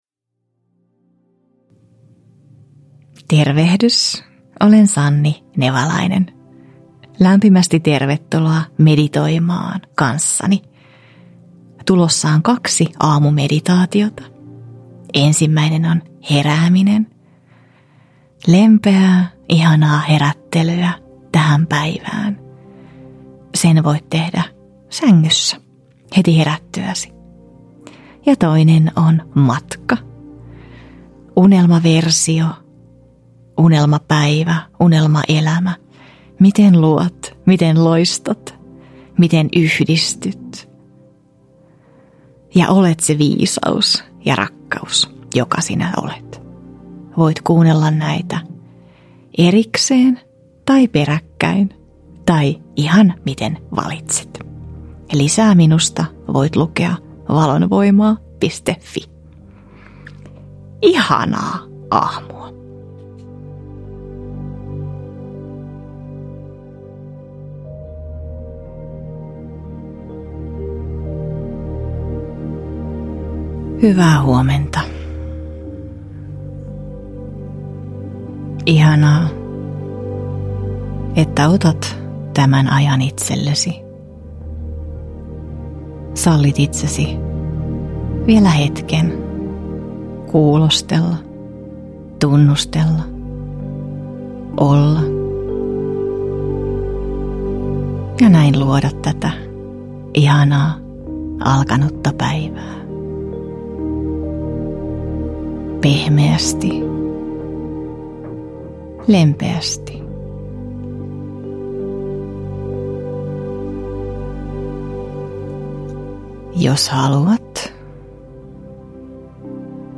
Aamumeditaatiot – Ljudbok
Herättelevä ja lempeä Aamumeditaatiot -äänite sisältää kaksi noin puolen tunnin meditaatiota: